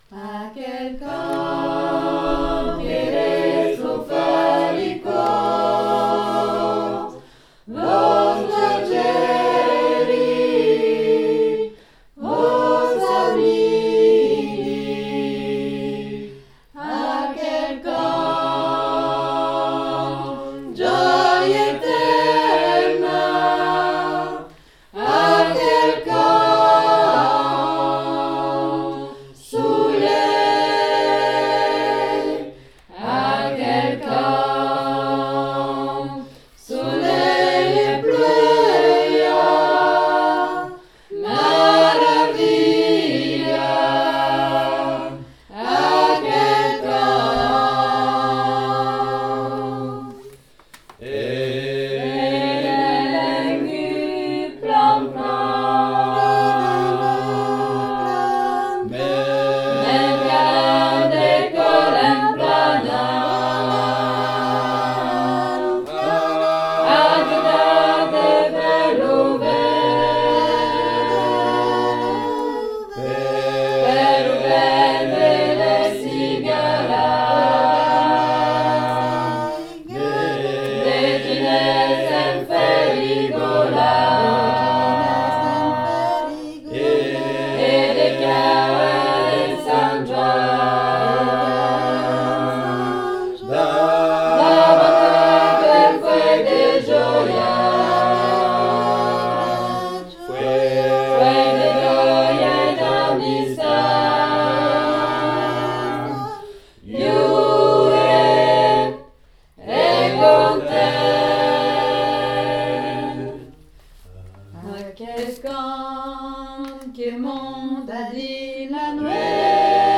Aquel_cant_TOUS.mp3